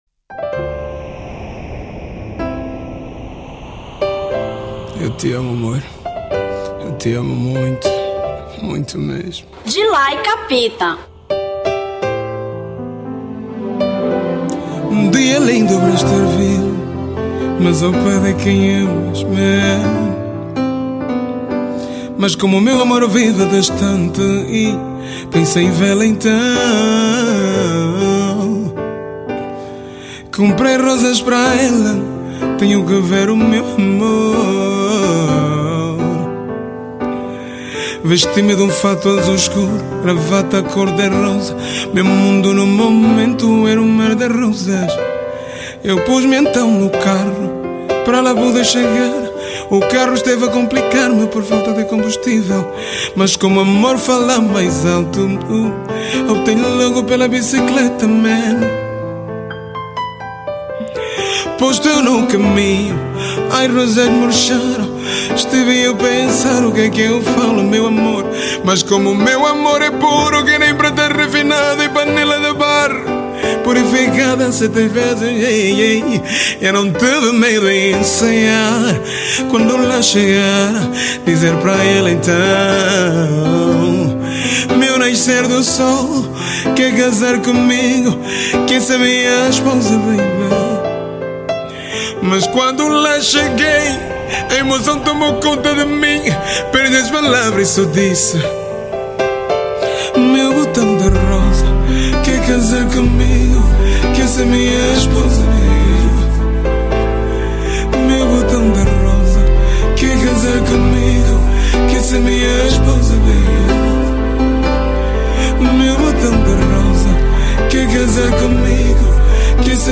Zouk 2014